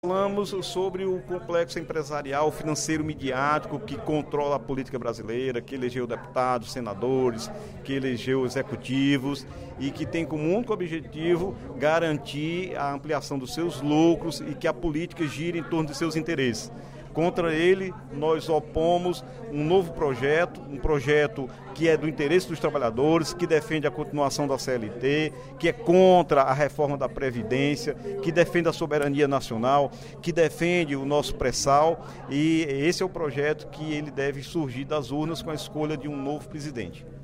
O deputado Dr. Santana (PT) comentou a conjuntura política brasileira durante o primeiro expediente da sessão plenária desta quarta-feira (31/05).